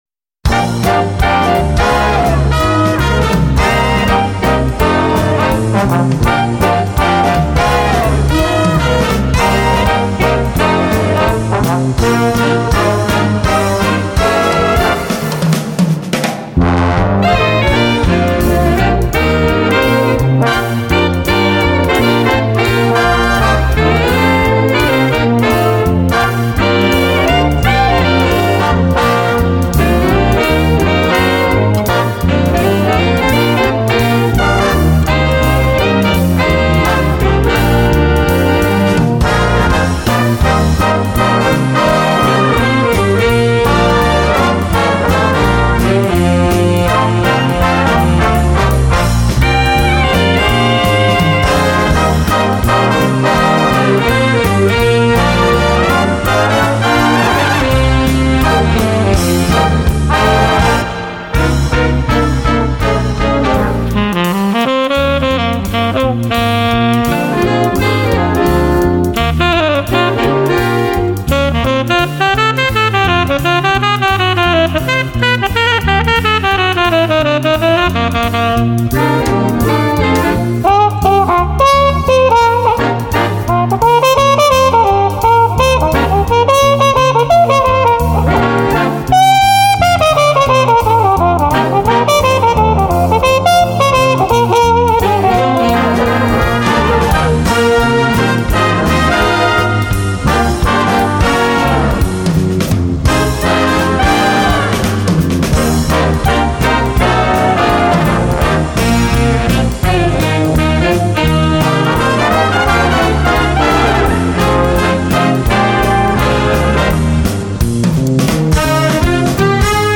Gattung: Bright Swing
A4 Besetzung: Blasorchester Zu hören auf